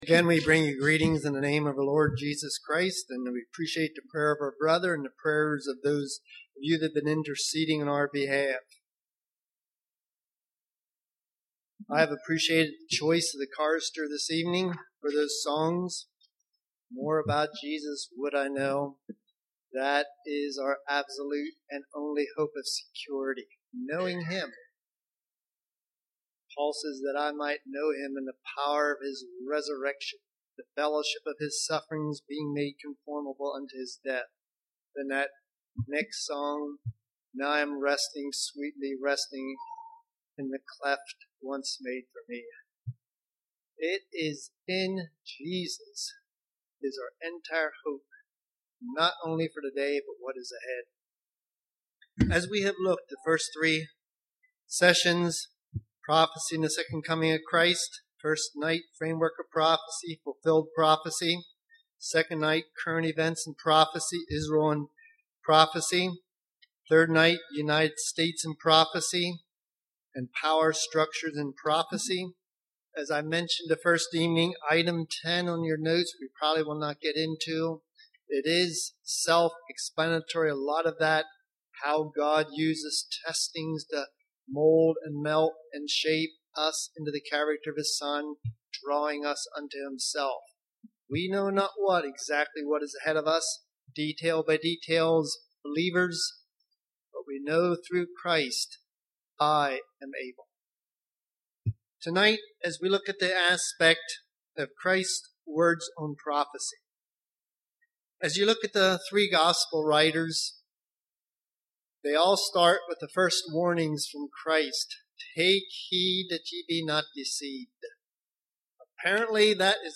Winter Bible Study 2010 Service Type: Winter Bible Study « Let Him In Prophecy and the Second Coming of Christ